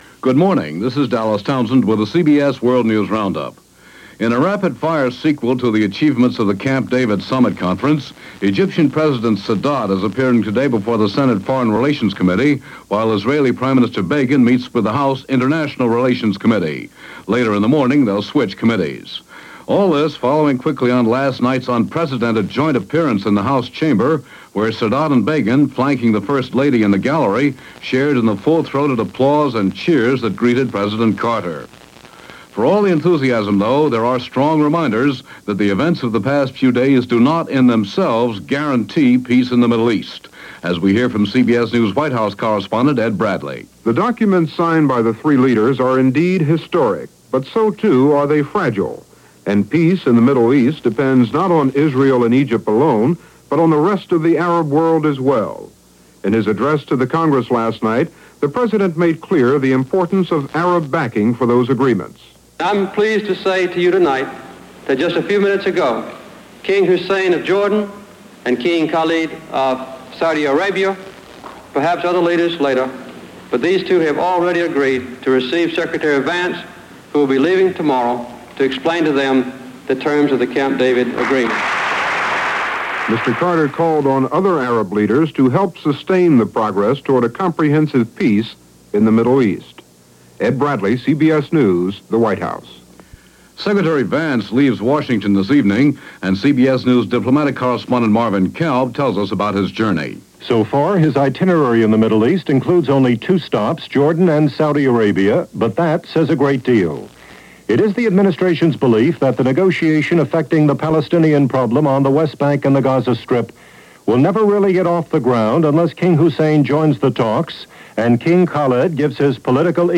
Here is The CBS World News Roundup for September 19, 1978.